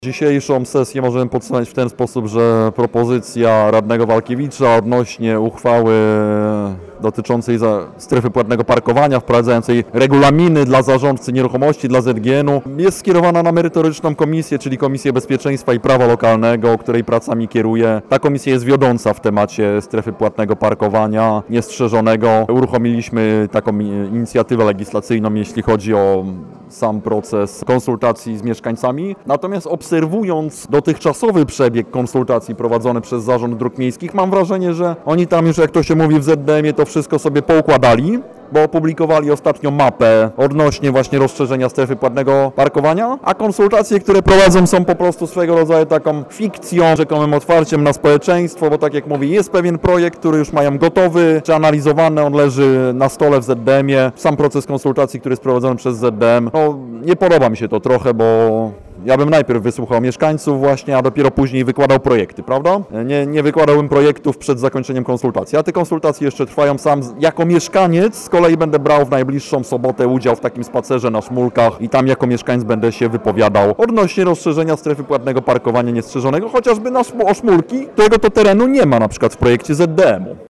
Wypowiedz radnego Mariusza Borowskiego z SLD: